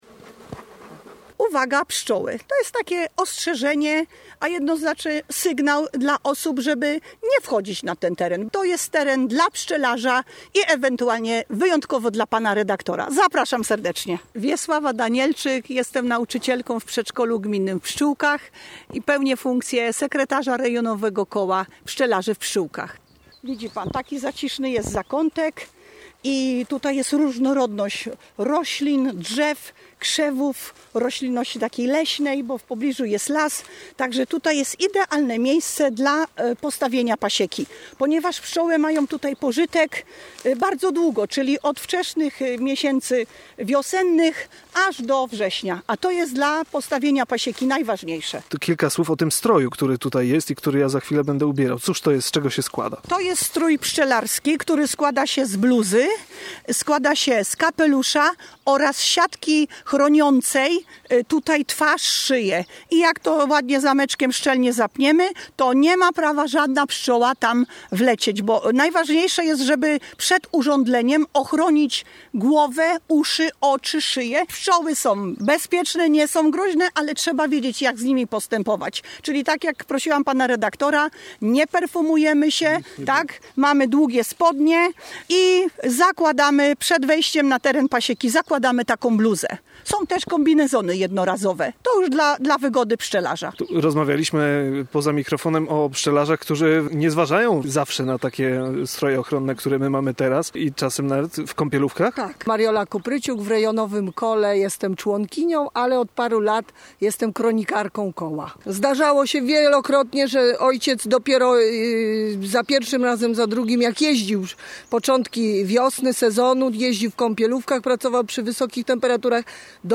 Ta przygoda zabierze państwa wprost do serca pasieki, mało tego – staniemy także przy otwartym ulu.